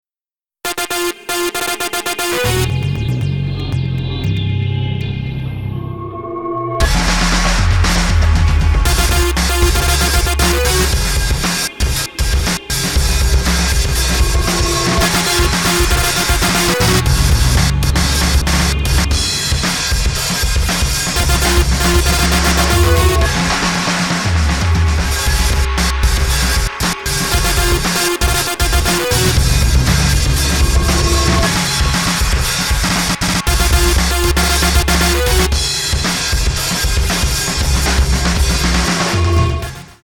レコーディングスタジオ : JEO